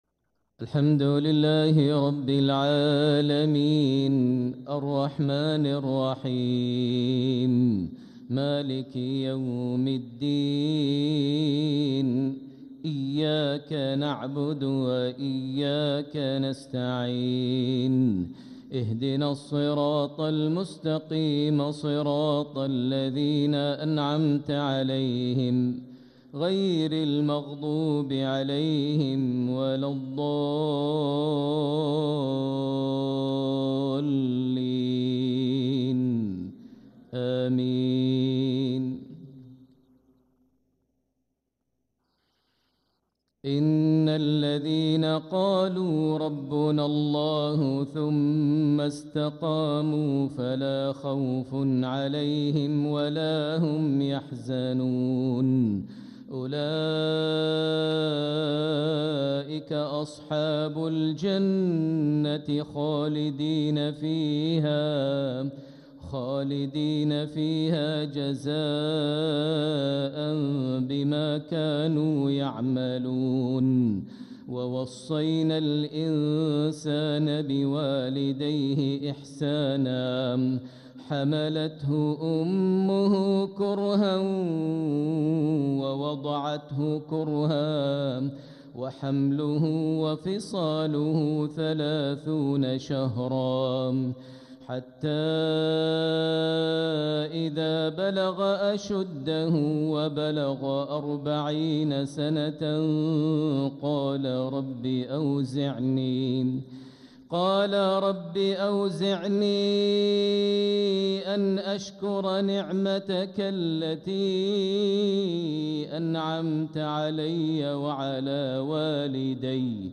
مغرب الأربعاء 4-3-1447هـ | من سورة الأحقاف 13-18 | Maghreb prayer from Surat Al-Ahqaf 27-8-202 > 1447 🕋 > الفروض - تلاوات الحرمين